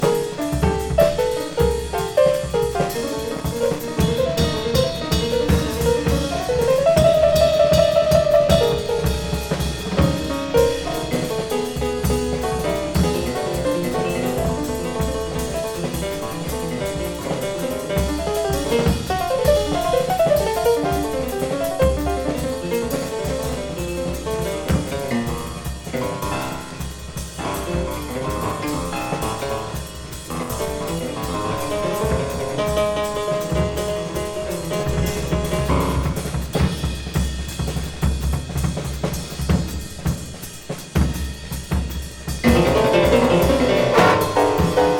スリリングと閃き、エモーショナルな瞬間も訪れる怒涛な演奏が最高です。